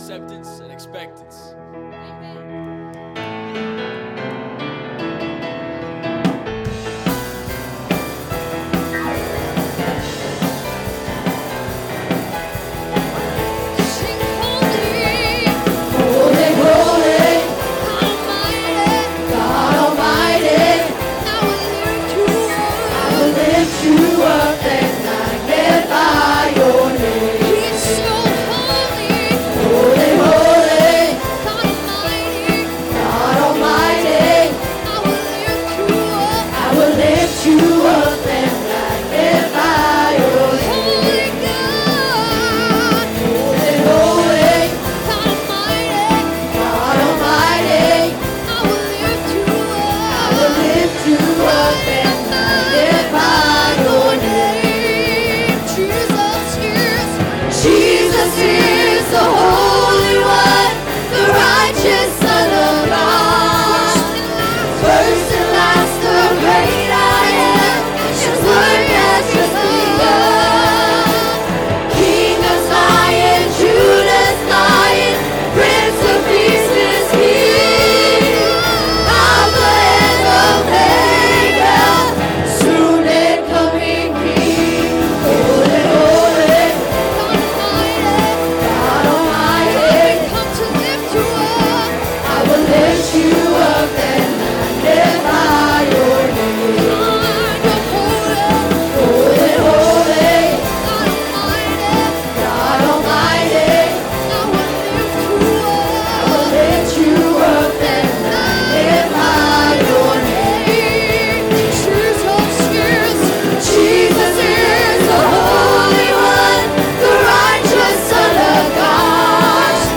Sermons by United Pentecostal Church